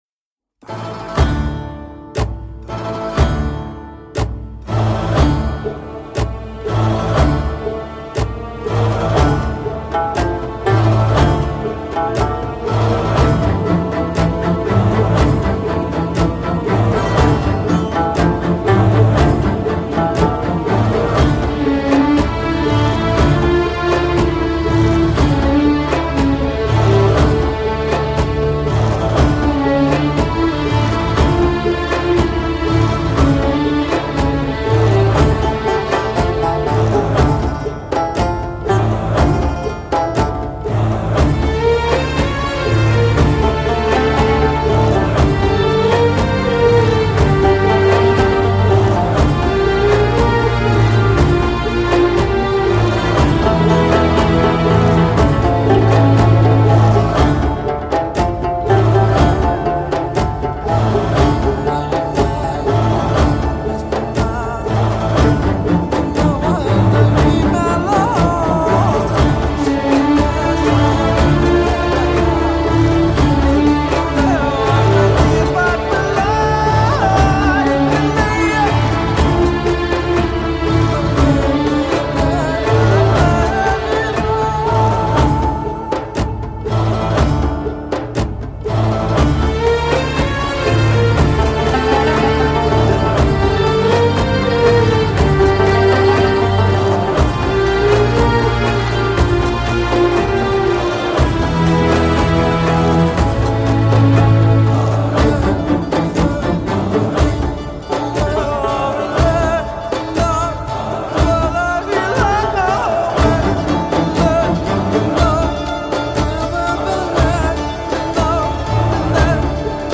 Free Download Instrumental